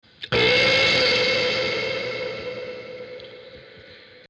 Play ARROTO no banheiro, Download and Share now on SoundBoardGuy!
burp_1.mp3